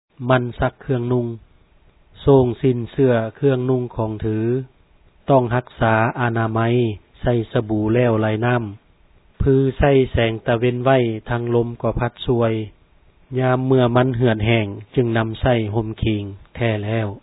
Exercise  27: Reading a Poem